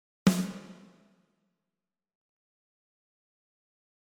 Ici j'ai laissé les ER faible mais j'ai mis le reveb delay à 0 :